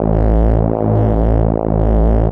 bseTTE52001hardcore-A.wav